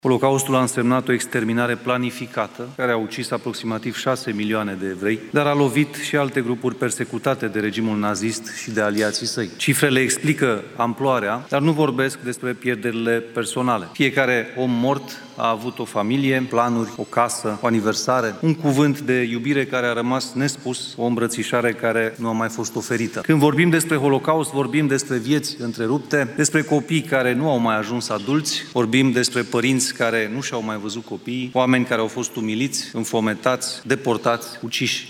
Ca în fiecare an, Federația Comunităților Evreiești din România a organizat ieri o ceremonie dedicată acestei zile. Președintele Senatului, Mircea Abrudean, a vorbit despre genocidul care a marcat Europa și întreaga lume.